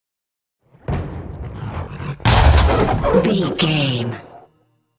Double hit with whoosh shot explosion
Sound Effects
dark
intense
woosh to hit